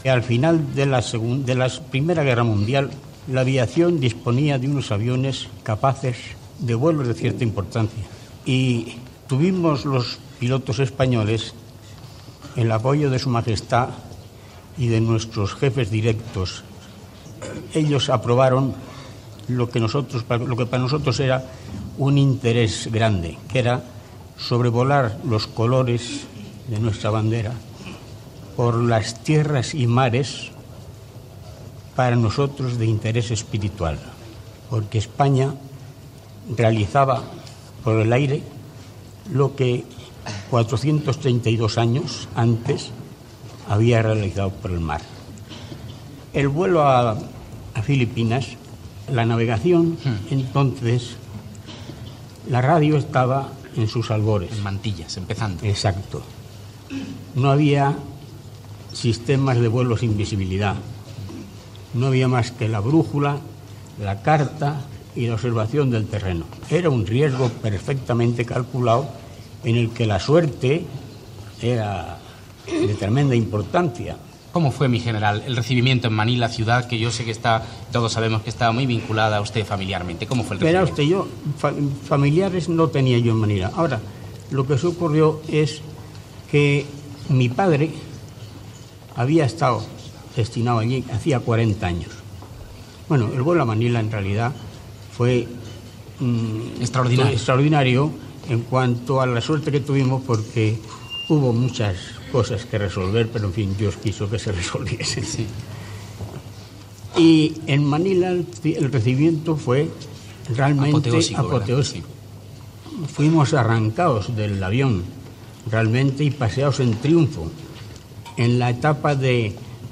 Entrevista al general Eduardo González Gallarza sobre el vol de la Escuadrilla Elcano. Tres avions espanyols, a l'any 1926, van volar des de Cuatro Vientos, a Madrid, fins a Manila.
Entreteniment